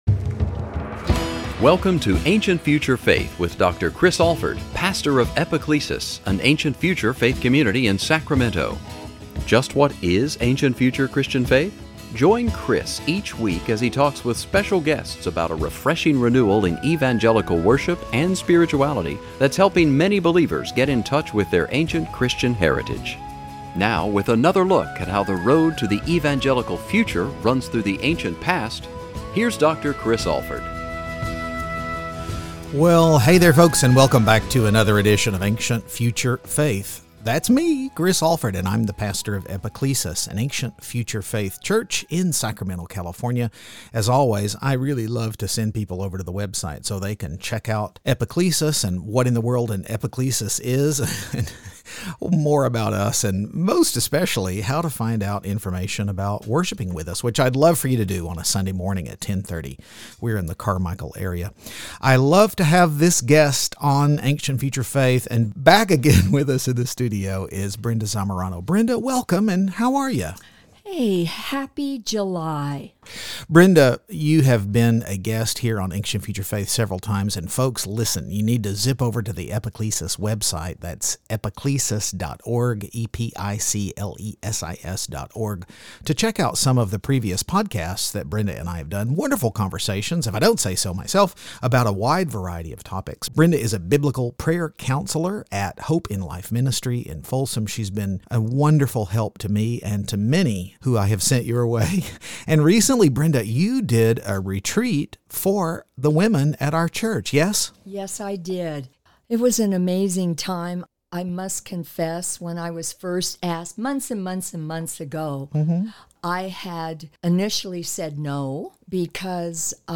Join us for a great conversation about how so many of the decisions women face right now go all the way back to the Garden of Eden.